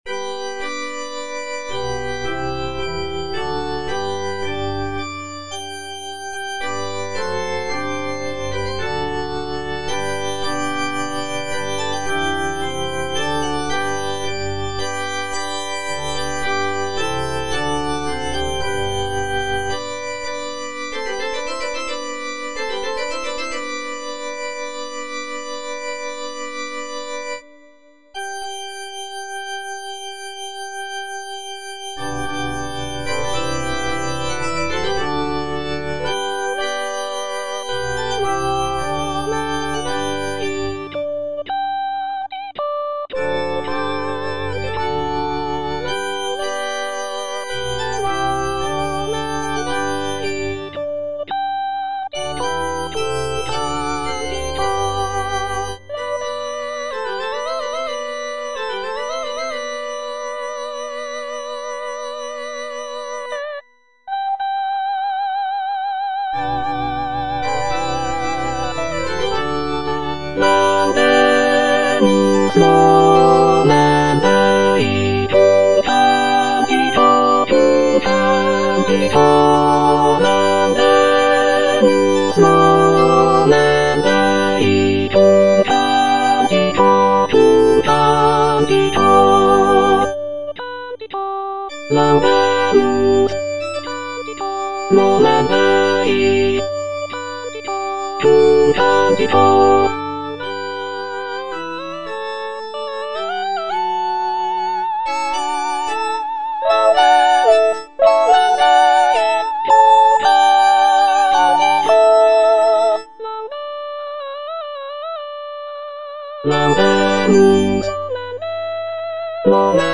(soprano I) (Emphasised voice and other voices) Ads stop
sacred motet